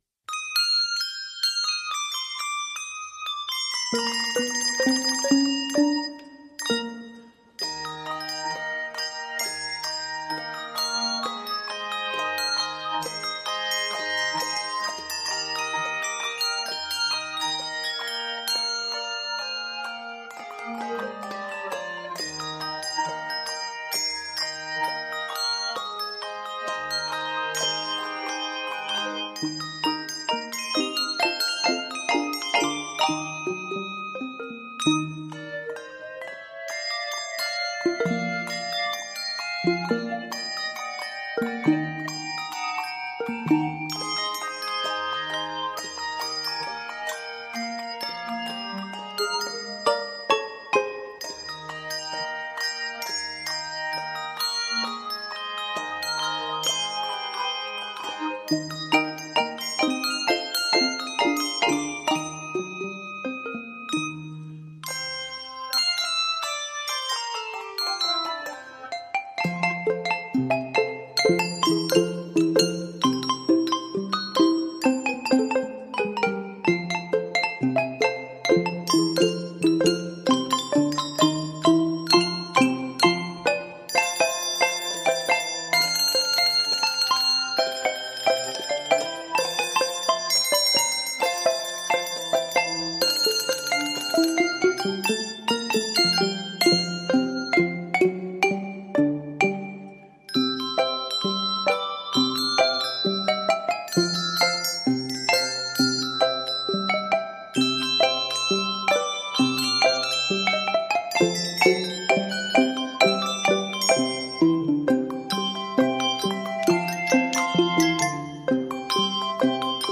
Drawing on ragtime